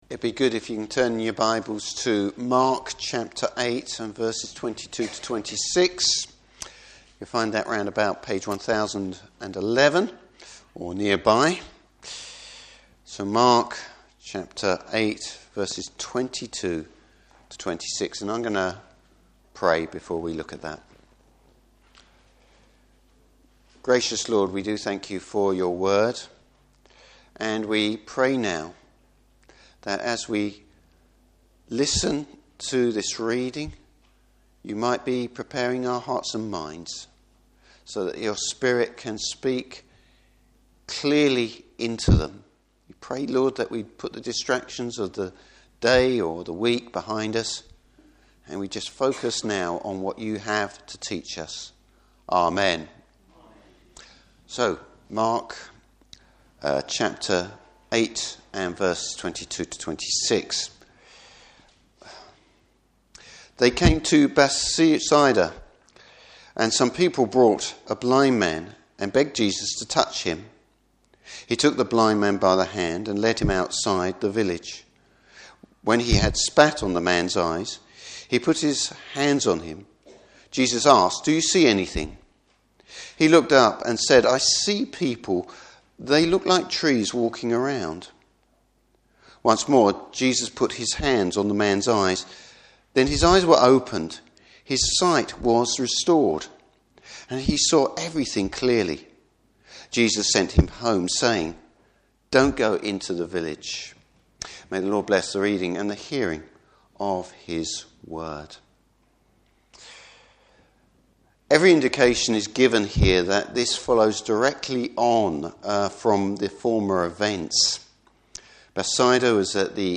Service Type: Morning Service A healing that acts as a parable concerning the Disciples lack of faith.